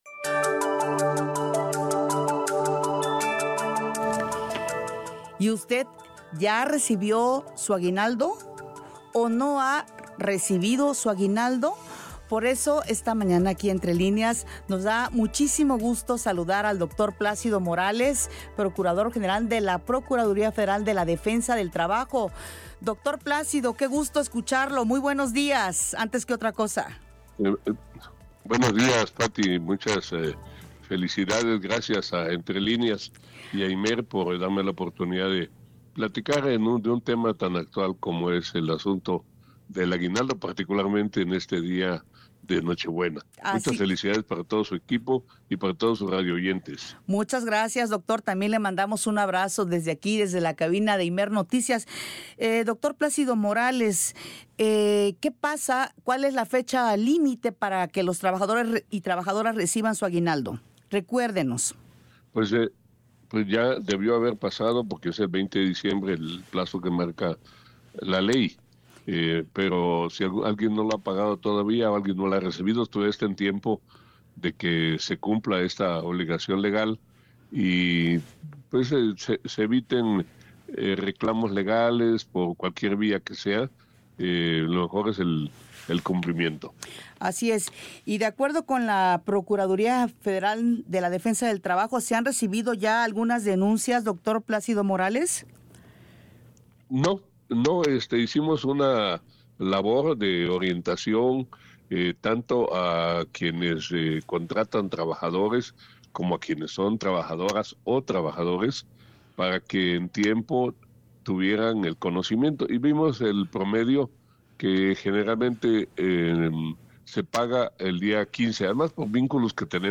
Escucha nuestra conversación con el Dr. Plácido Morales Vázquez, titular de la PROFEDET, sobre el pago del aguinaldo.